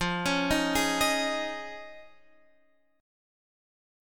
Listen to Fm7#5 strummed